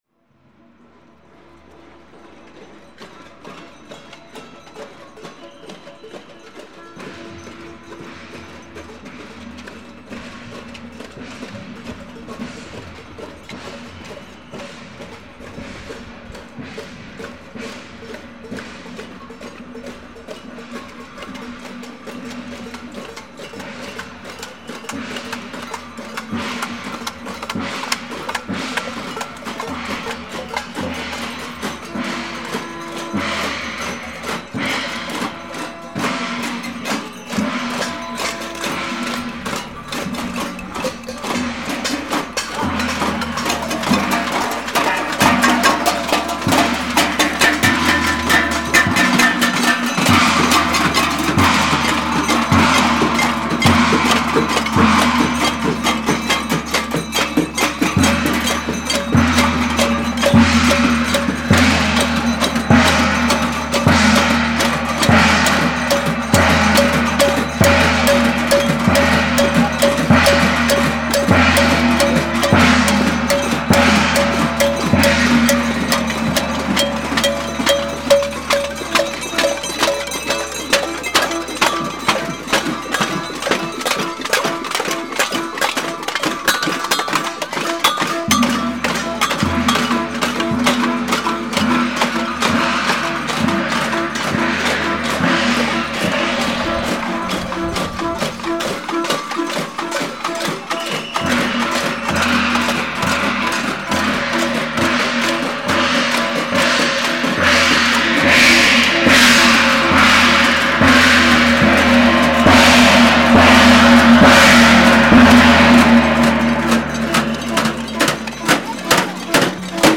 Skrifað í Mannlíf | Merkt Alþingi, Austurvöllur, Búsáhaldabylting, Fólk, Korg MR1000, Mótmæli, Protest, Sennheiser ME62 | Færðu inn athugasemd
Hrunameistarinn og frjálhyggjupostulinn Davíð Oddson var þá flúinn land með öðrum skúrkum sem sumir, dagana áður, hreynsuðu hundruð milljónir króna af reikningum til að koma þeim undan réttvísinni Hljóðmyndin sem hér heyrist var tekin upp fyrir framan Alþingishúsið síðdegis 22. janúar 2009, daginn eftir þá örlagaríku nótt þar sem minnstu munaði að götur Reykjavíkur hefðu verið þaktar blóði.
Tekið var upp í DSF sniði 1bit/5,644Mhz á Korg MR1000 með Sennheiser ME62 sem mynduðu 90°horn á T-stöng. Upptakan hefst þar sem komið er frá Dómkirkjuni. Farið er inn í mannþröngina framan við Alþingishúsið, gengnir nokkrir hringir og þaðan frá aftur.